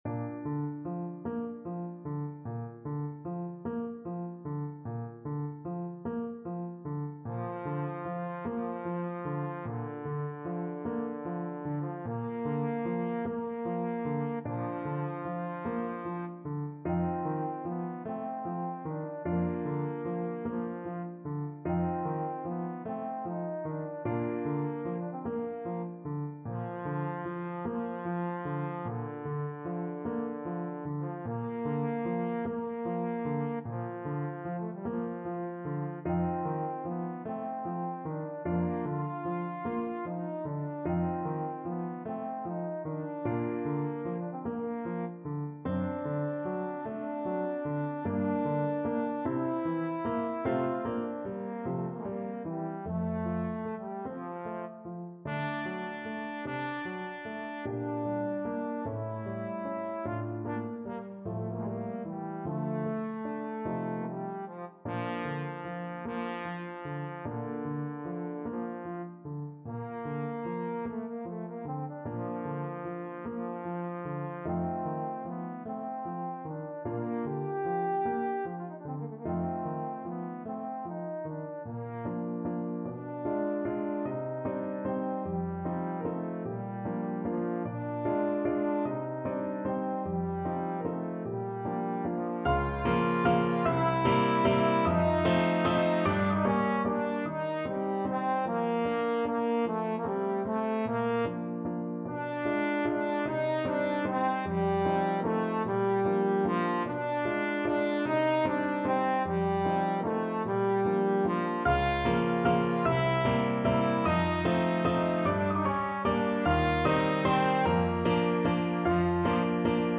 Trombone
4/4 (View more 4/4 Music)
Bb major (Sounding Pitch) (View more Bb major Music for Trombone )
Largo
Classical (View more Classical Trombone Music)